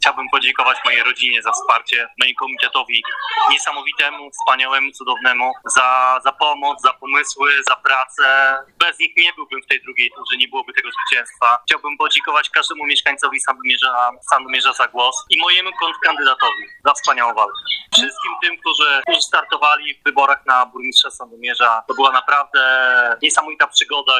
Oto pierwszy komentarz Pawła Niedźwiedzia po ogłoszeniu – nieoficjalnych jeszcze – wyników wyborów: